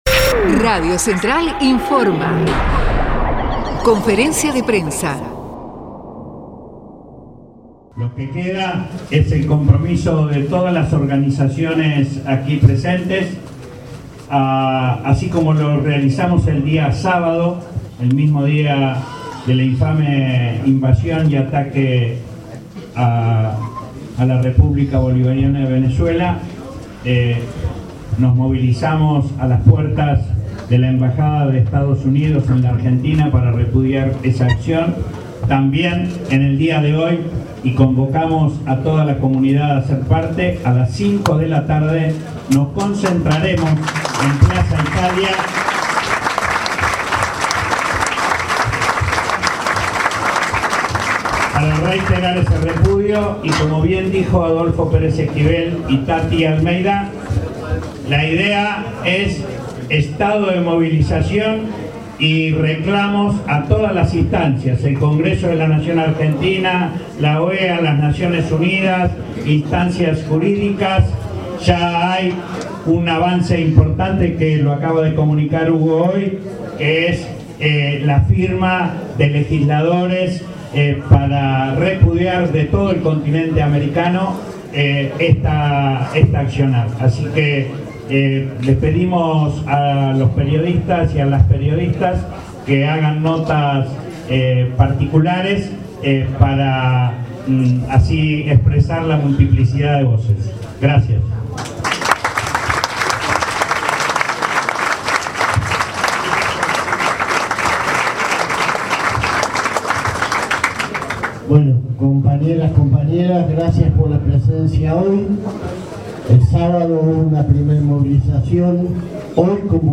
VENEZUELA BAJO ATAQUE DE EE.UU. - CONFERENCIA DE PRENSA: Testimonios CTA
venezuela_conf_prensa_enero5.mp3